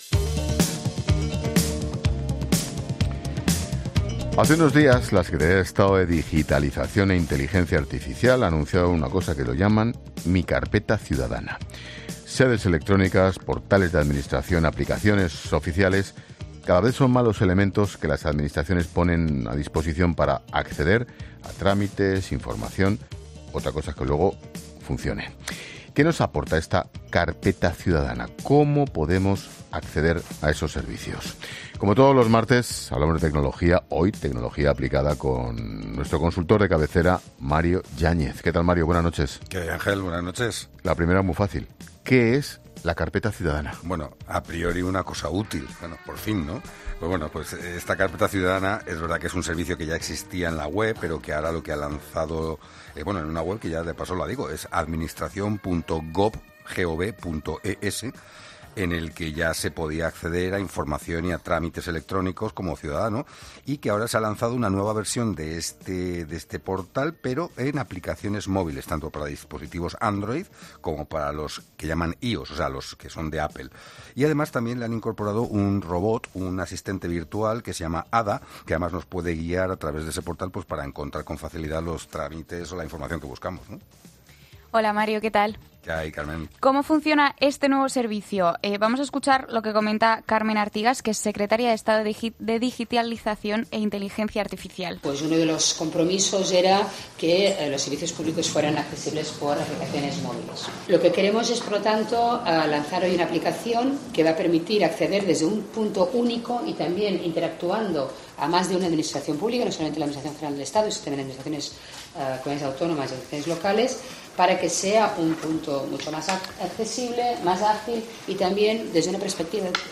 Ángel Expósito reflexiona en 'La Linterna' sobre qué es la Carpeta Ciudadana y para qué sirve